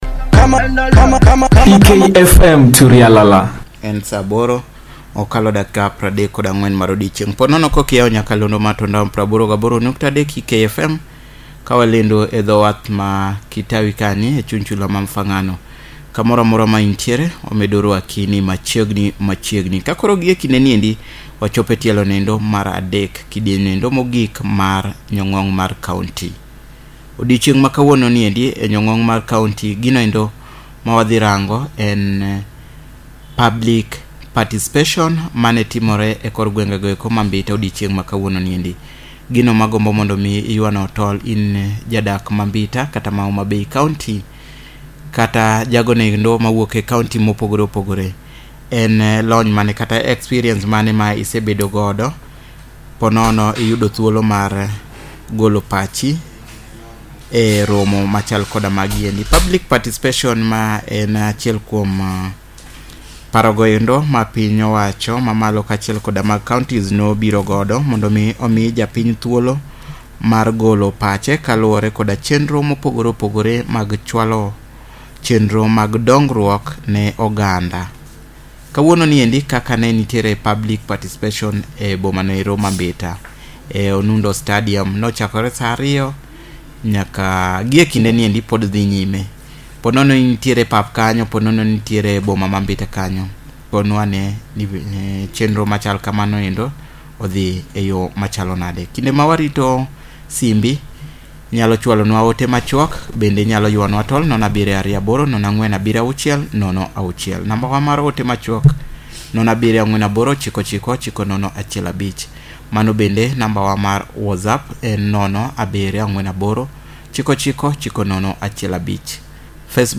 This a live coverage of public forum on public participation done Ekialo Kiona Radio station. The Public forum was part of ‘our county our responsibility’ project took place at Mbita town and its purpose was to bring together community members, community media, civil society organisation and county officials and this was a platform for them to engage on the existing challenges on public participation and ways to improve.